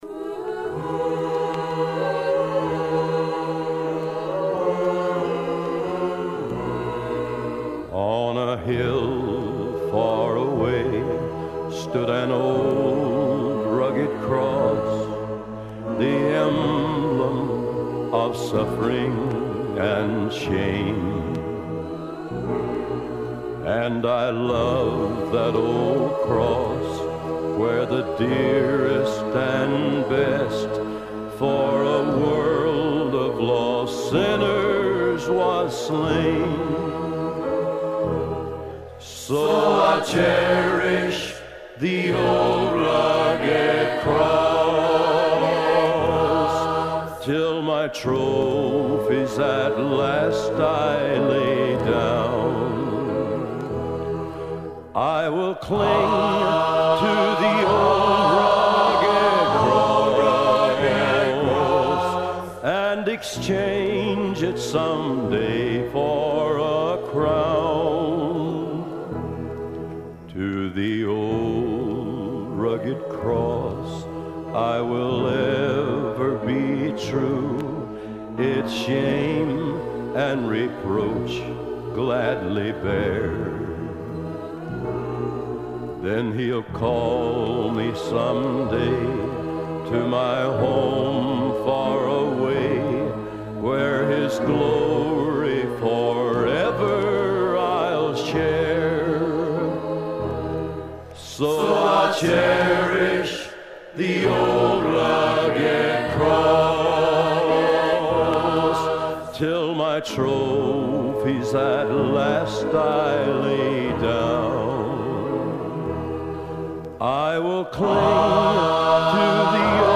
Side two was with school chorus.